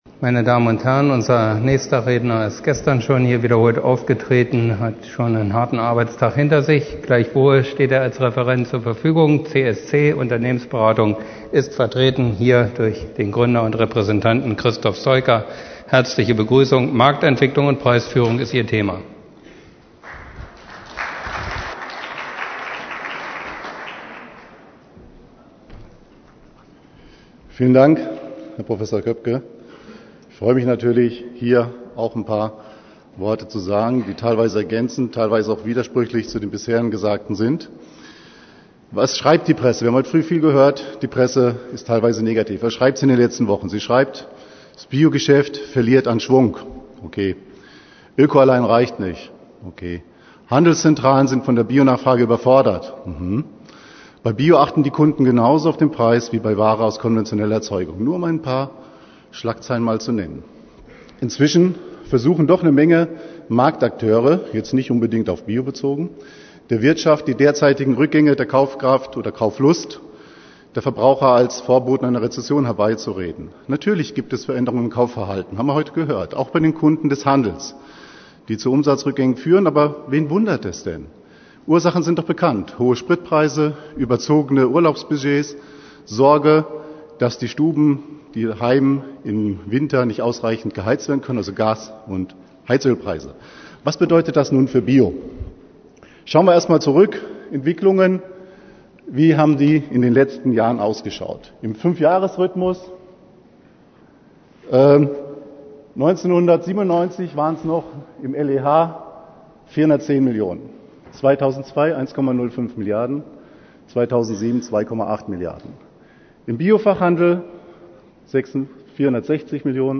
Impulsvortrag